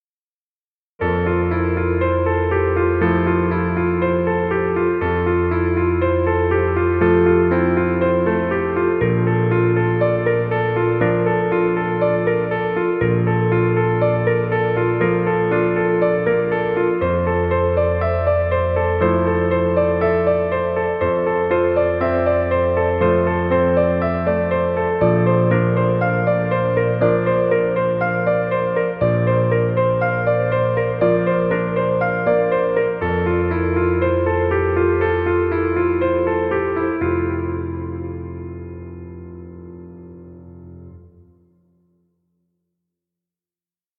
Piano music. Background music Royalty Free.